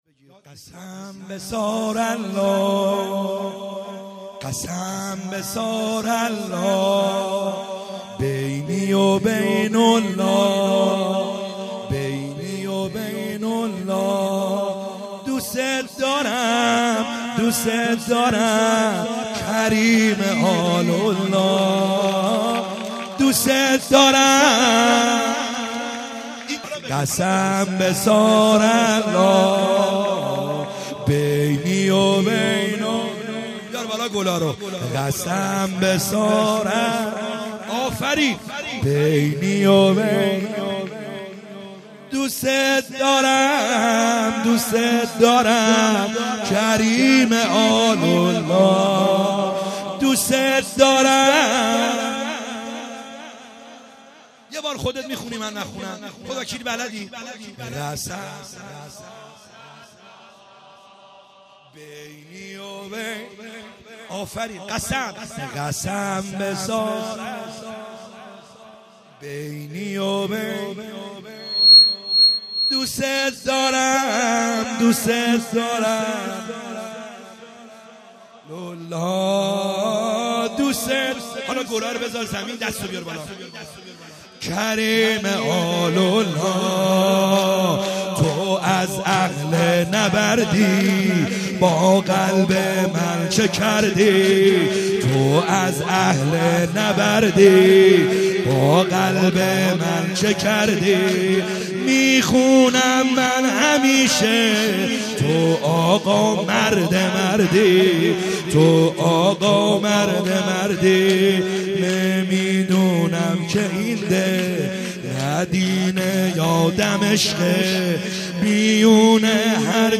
خیمه گاه - بیرق معظم محبین حضرت صاحب الزمان(عج) - سرود | قسم به ثارالله